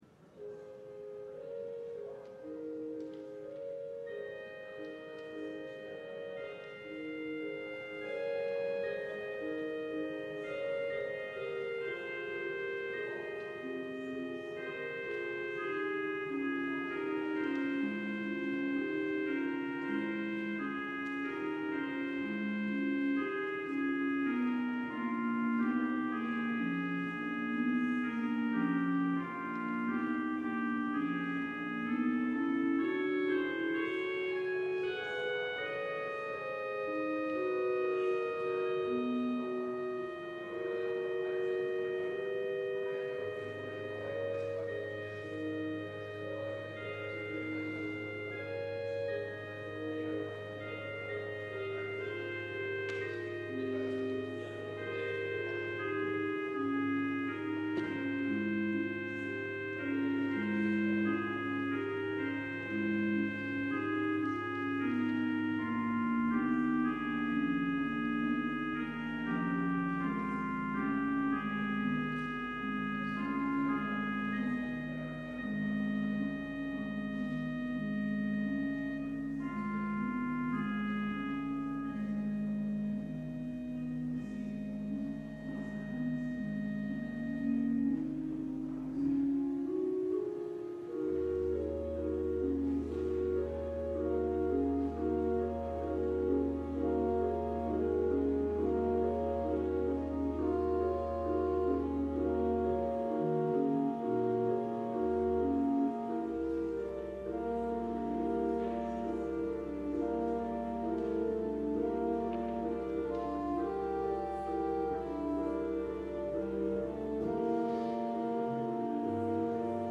LIVE Morning Worship Service - God’s Covenant with Abraham
Congregational singing—of both traditional hymns and newer ones—is typically supported by our pipe organ.